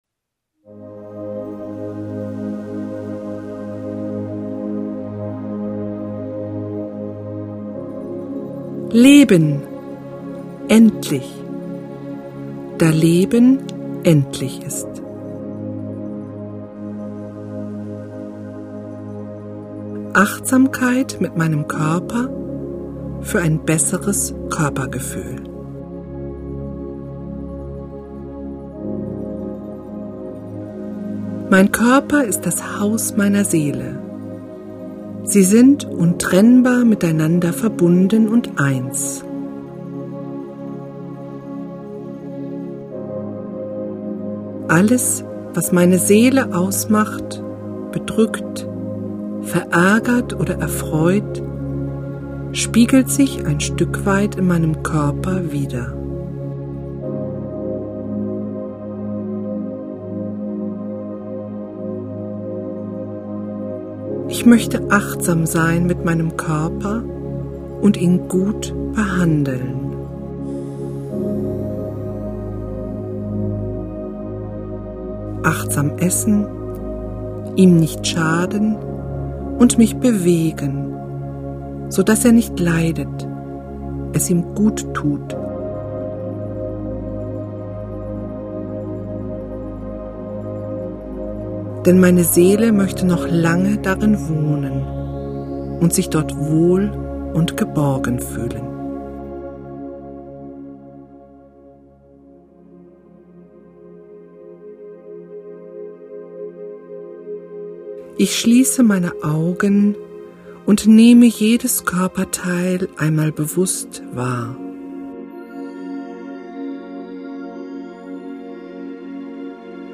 Hörprobe (hier mp3 direkt)
Achtsamkeitsübungen Hörprobe   44:00 min download